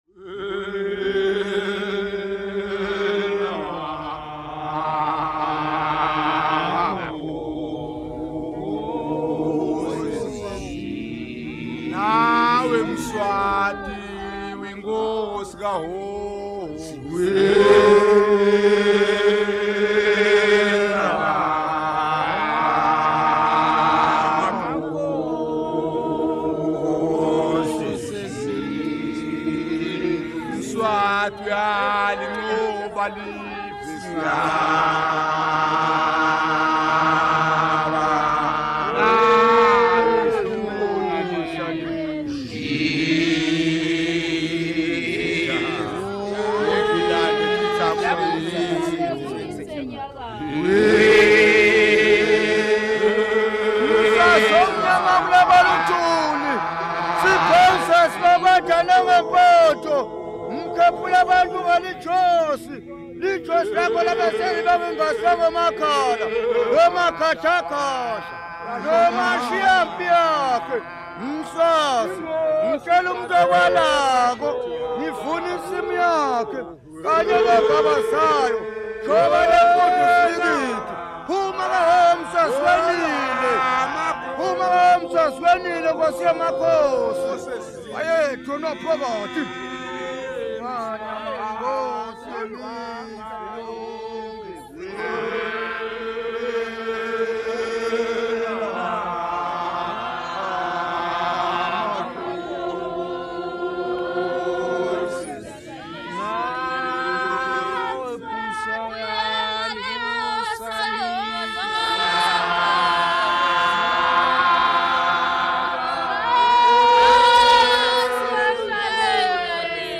Group of men and girls from the Queen mother's village
Field recordings
Folk songs, Swazi
Umgubo regimental song
96000Hz 24Bit Stereo